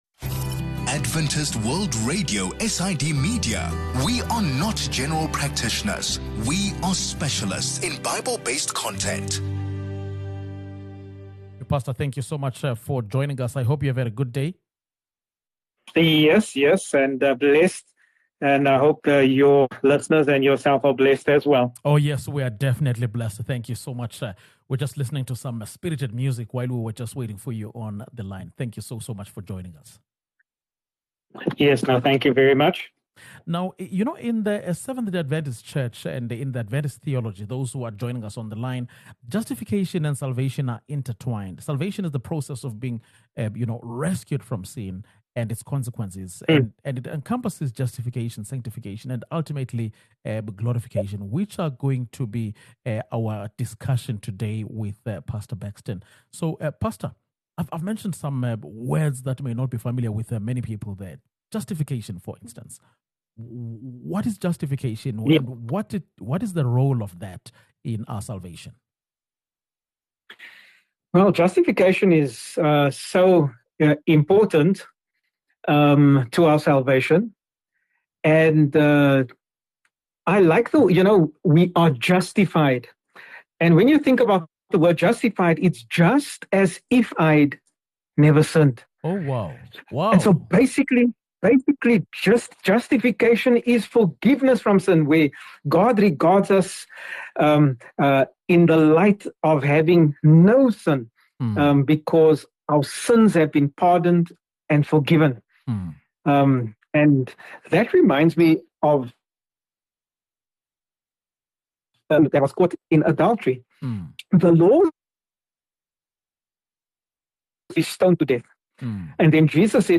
A lesson on the connection between justification, sanctification and salvation.